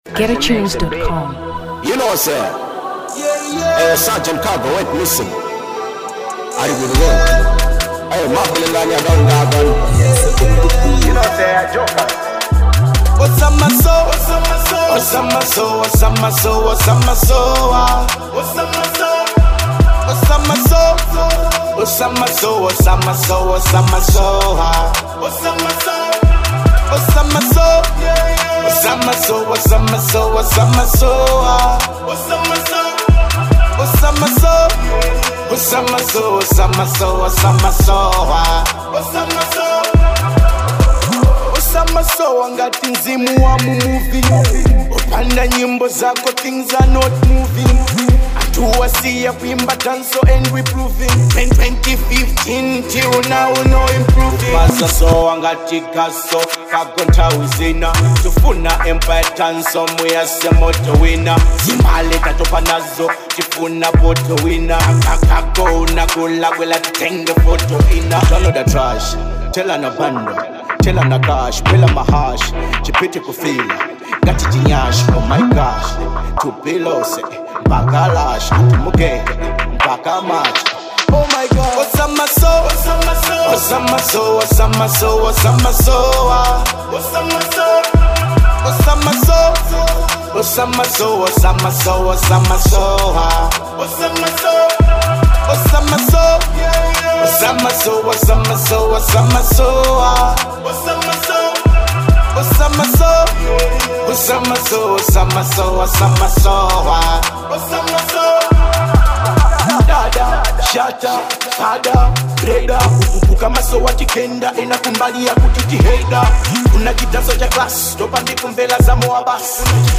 Drill/Dancehall 2023 Malawi